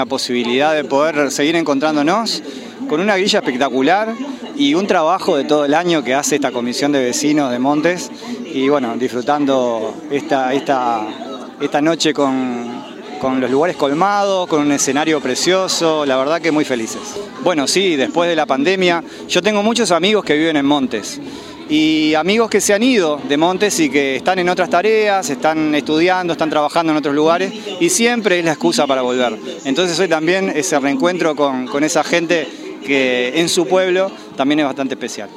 sergio_machin_director_general_de_cultura_1.mp3